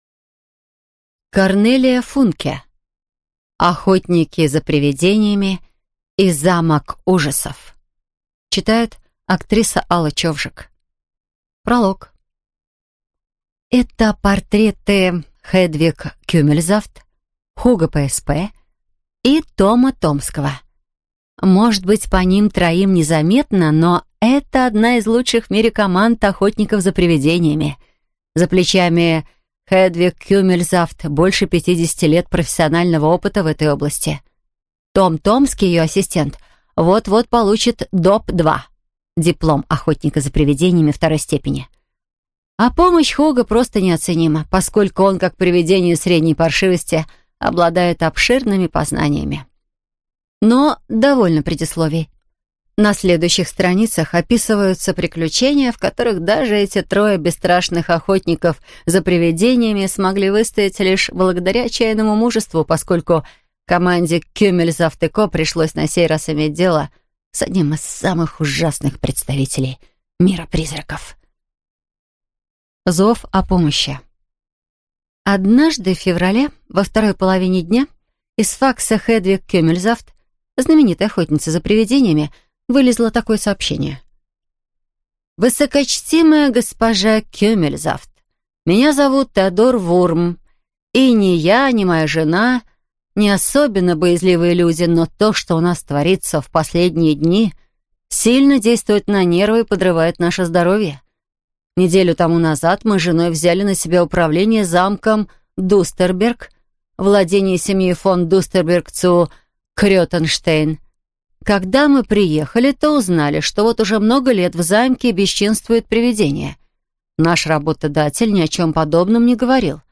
Аудиокнига Охотники за привидениями и замок ужасов | Библиотека аудиокниг